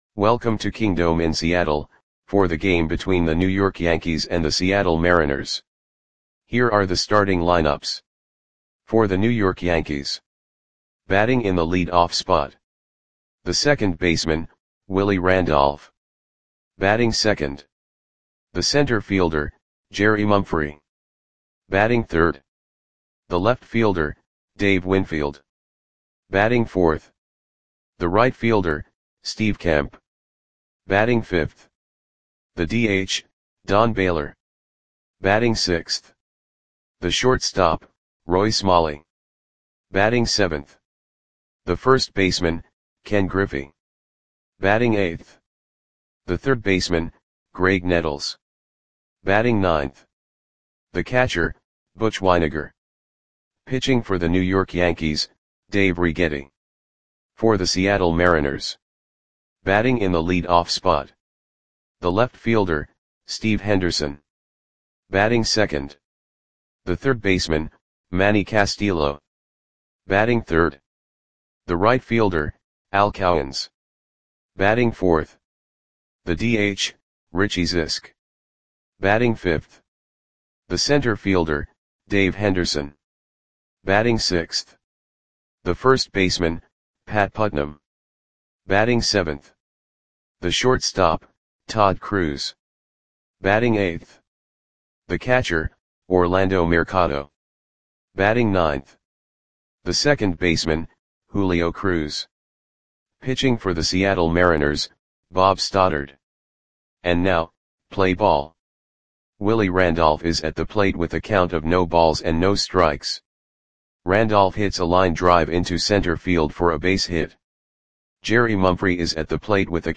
Audio Play-by-Play for Seattle Mariners on April 7, 1983
Click the button below to listen to the audio play-by-play.